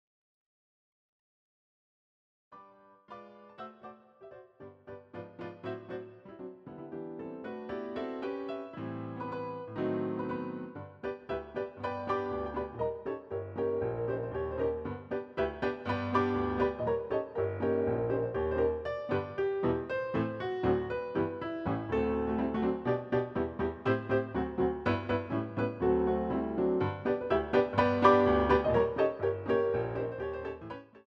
using the stereo sampled sound of a Yamaha Grand Piano